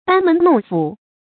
注音：ㄅㄢ ㄇㄣˊ ㄋㄨㄙˋ ㄈㄨˇ
班門弄斧的讀法